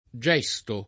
gesto [ J$S to ] s. m.